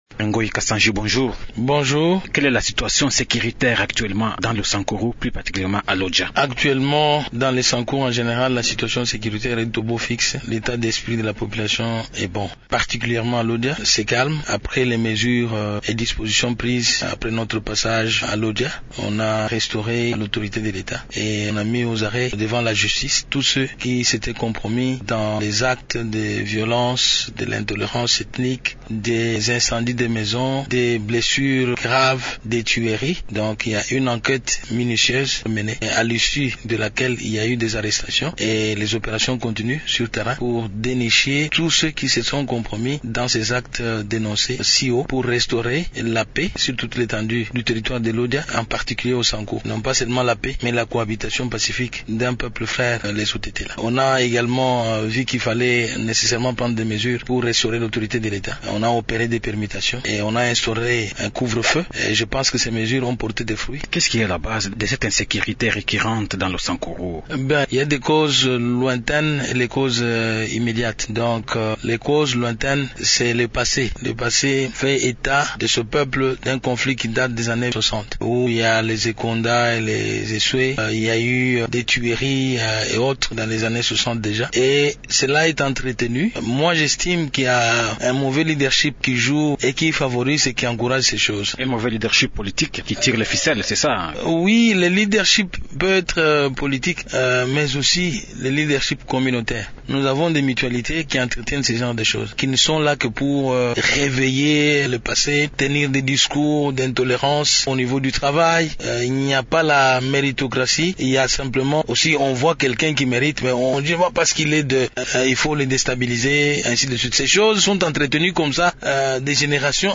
Le gouverneur Kasanji s’entretient du développement de sa province